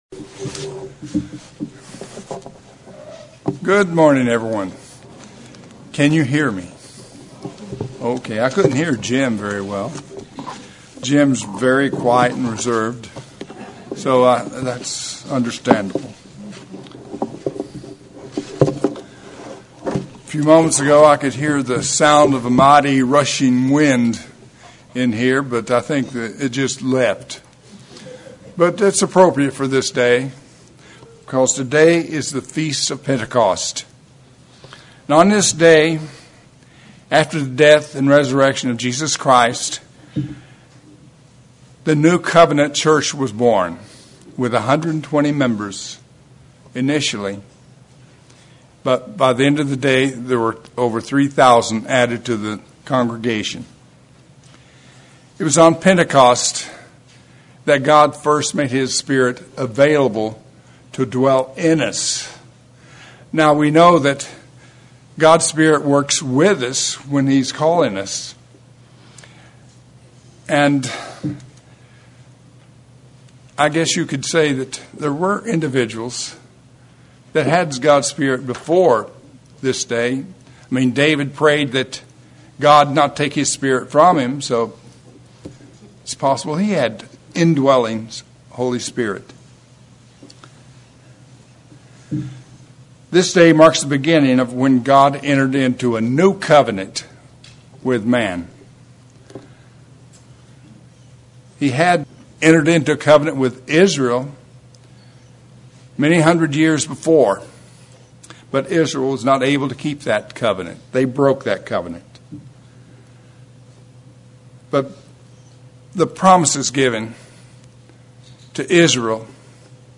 This sermon examines Pentecost and shows how it is the beginning of the fulfillment of God’s promise to Abraham. It examines why the old covenant failed and the need for a new covenant.